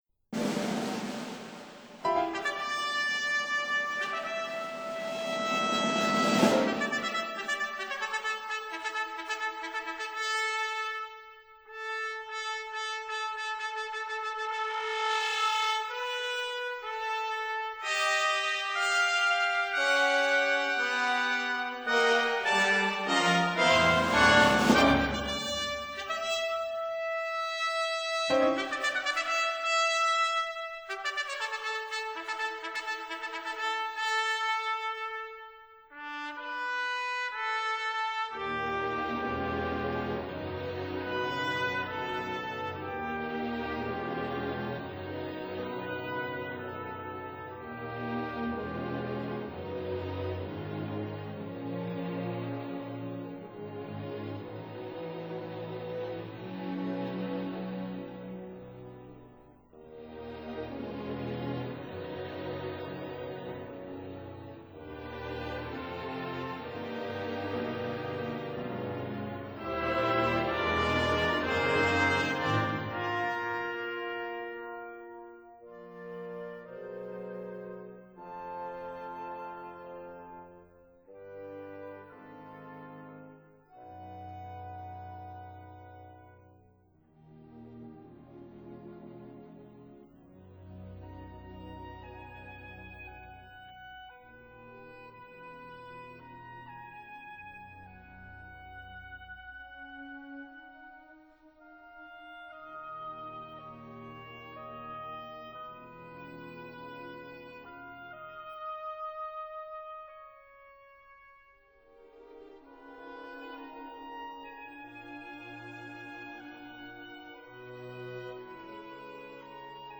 13 - 15 Symphony for organ and orchestra * [23'52]